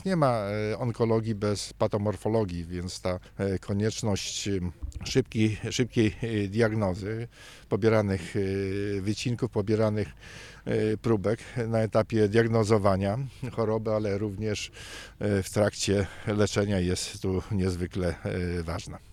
Potrzebna temu szpitalowi, ale przede wszystkim Dolnoślązakom – mówi Cezary Przybylski, wiceprzewodniczący Sejmiku Województwa Dolnośląskiego oraz przewodniczący Rady Społecznej przy Wojewódzkim Szpitalu Specjalistycznym.